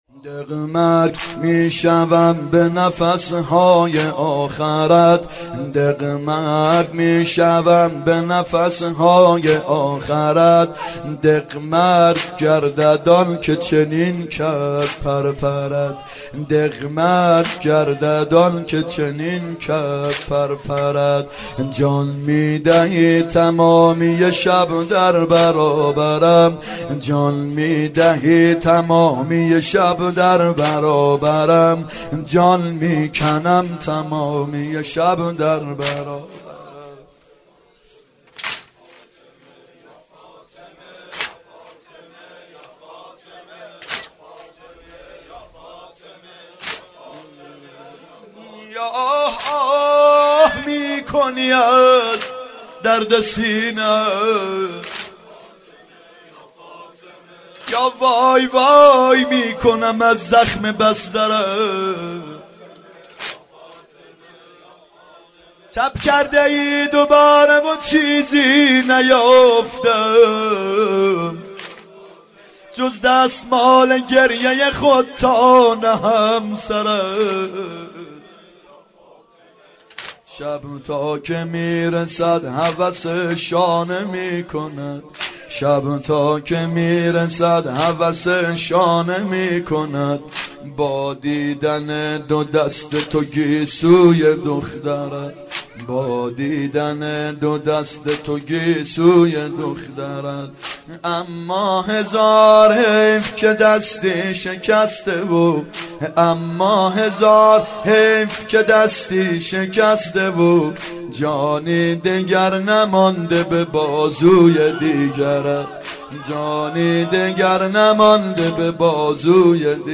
فاطمیه اول هیئت یامهدی (عج)